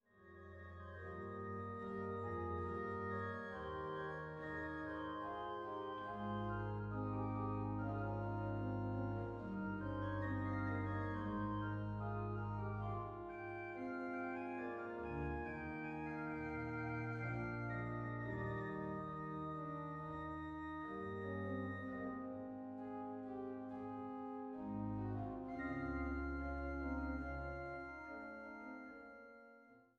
Hildebrandt-Orgel in Langhennersdorf